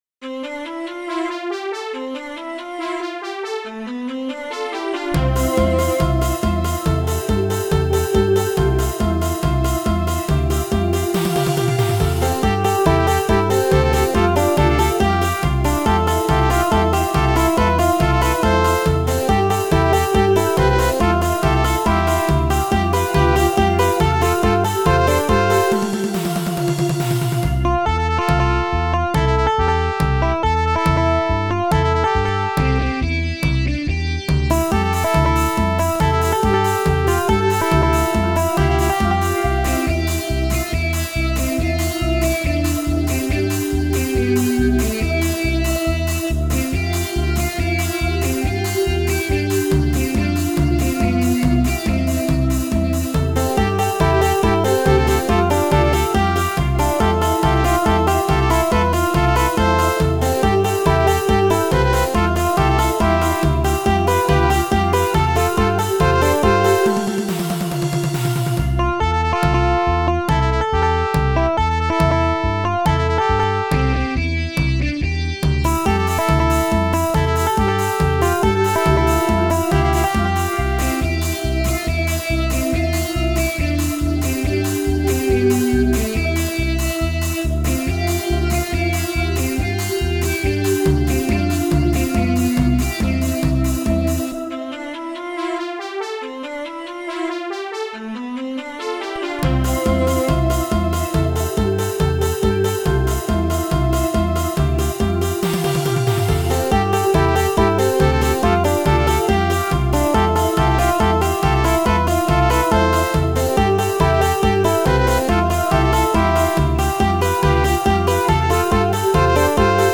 Песня чисто танцевальная, мелодия без голоса вот: